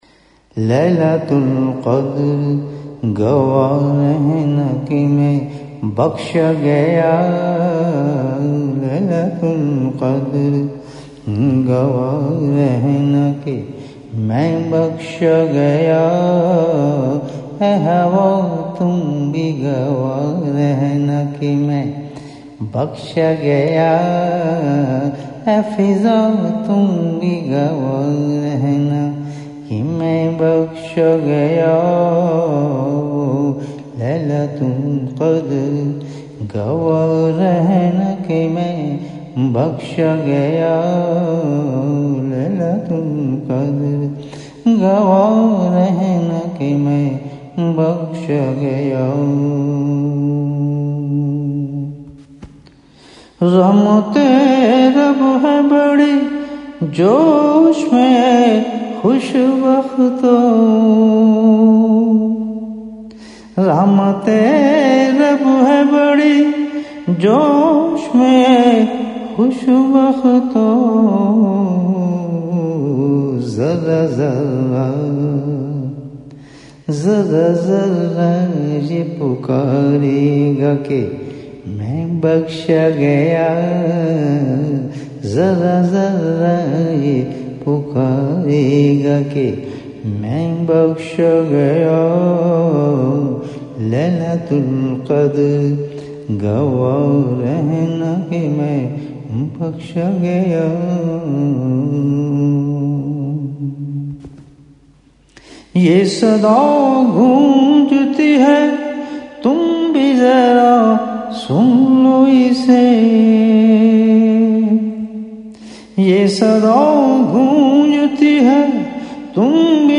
Recited beautifully